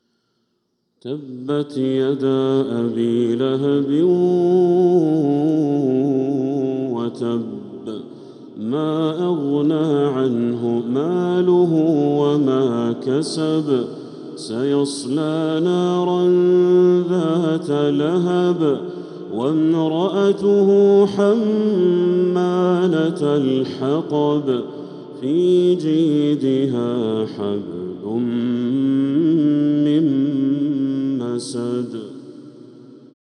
سورة المسد كاملة | ذو القعدة 1446هـ > السور المكتملة للشيخ بدر التركي من الحرم المكي 🕋 > السور المكتملة 🕋 > المزيد - تلاوات الحرمين